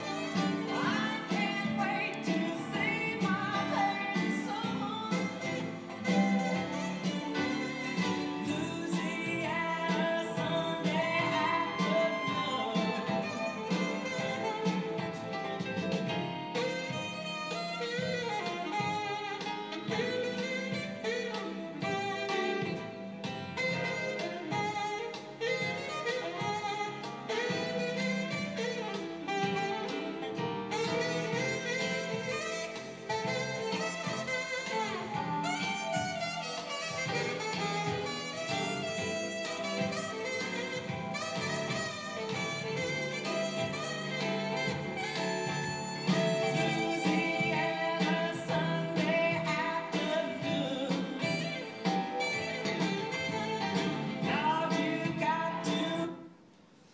Awful smooth jazz soundcheck track